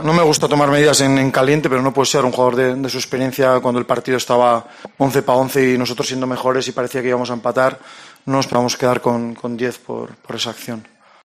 “Es una pena que el Chimy marque dos goles en casa y no sirvan para sumar por cometer errores de bulto”, ha finalizado Arrasate su comparecencia posterior al partido, al valorar el gran partido de su mejor jugador